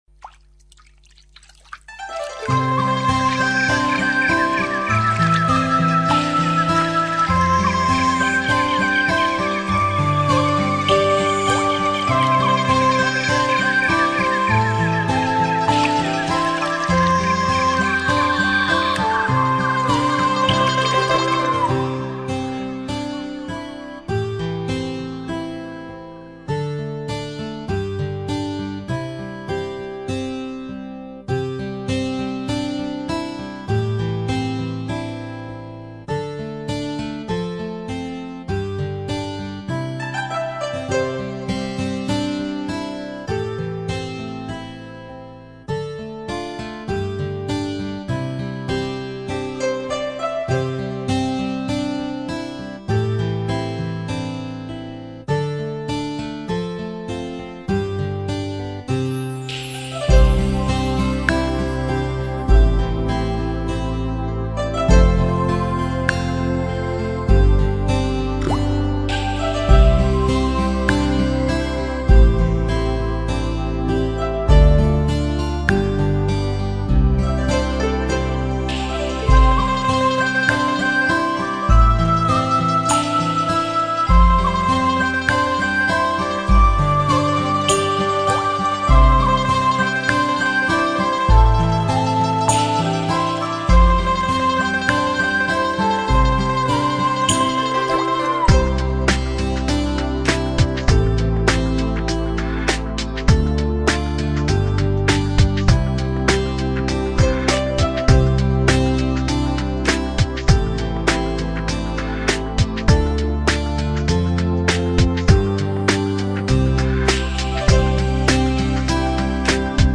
高品质纯音乐伴奏
无男声伴奏。
淡淡的清音响起 如入梦境 回味无穷。。。
不离不弃。不徐不疾。淳朴清和。。。